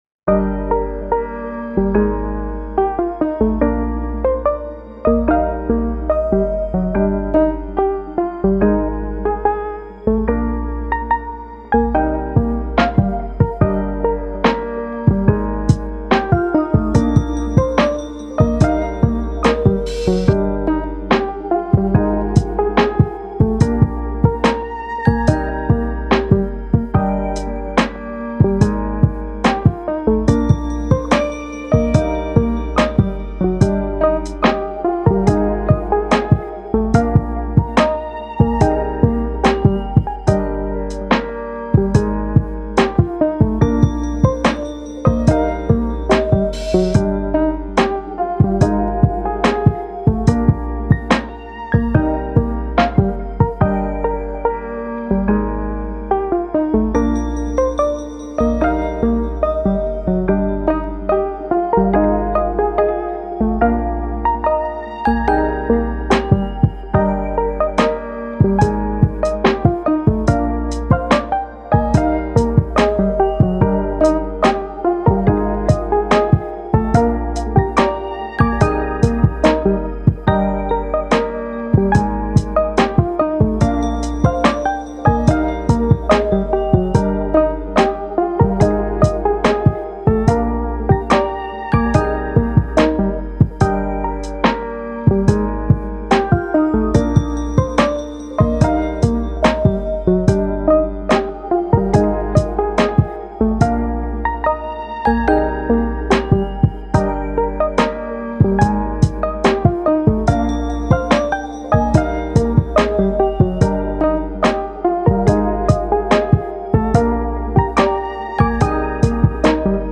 チル・穏やか